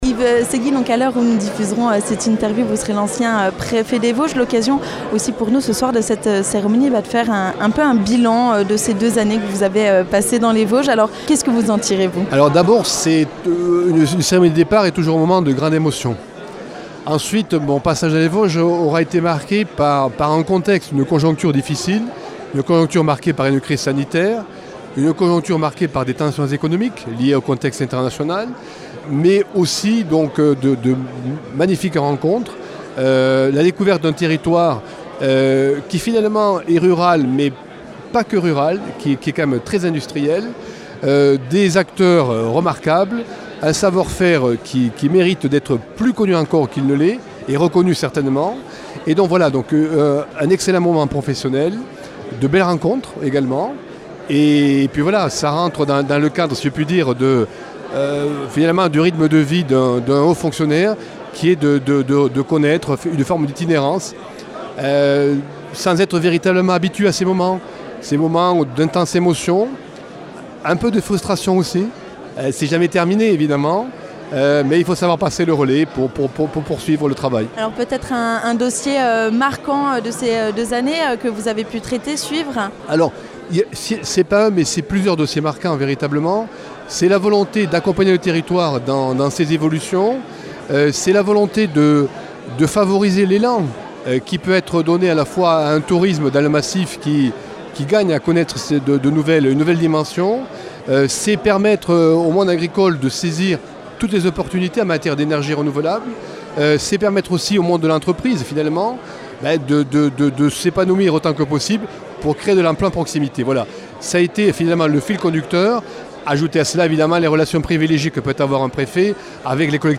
Dernière entrevue avec Yves Séguy, ancien préfet des Vosges
Ecoutez Yves Séguy, ancien préfet des Vosges, sur Vosges FM !